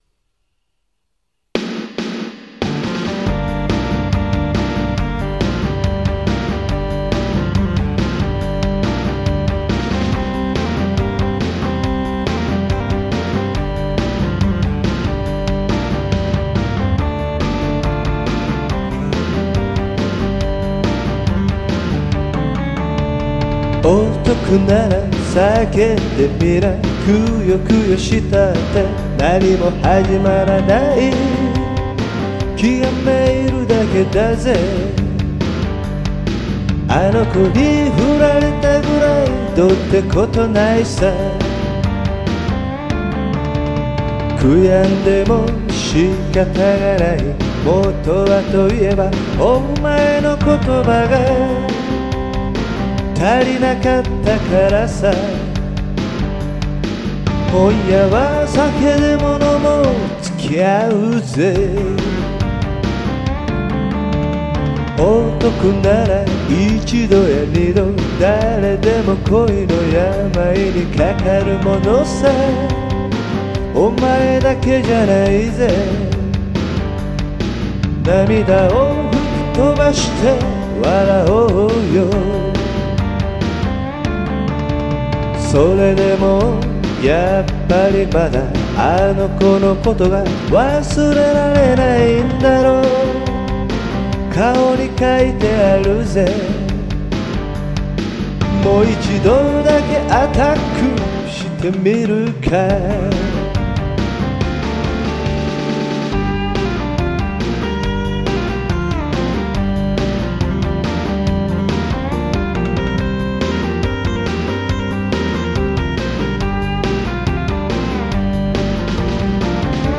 Rock'n'roll (up tempo)